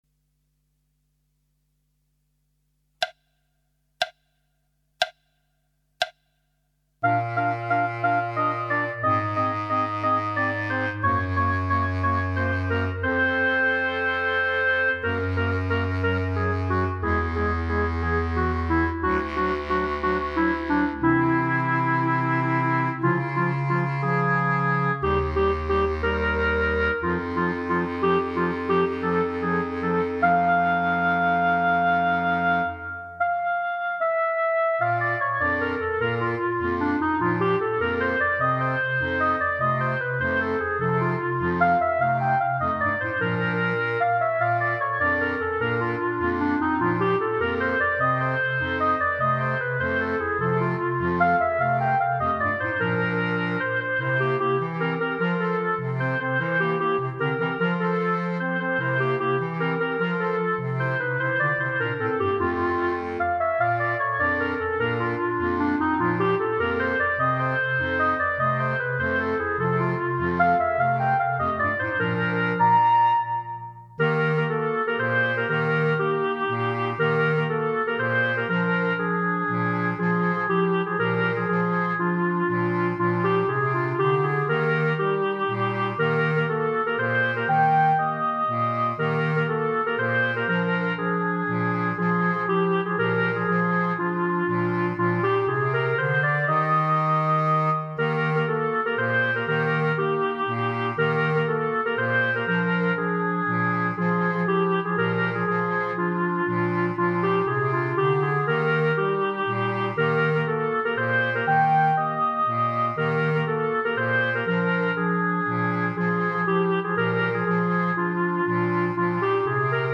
minus Clarinet 3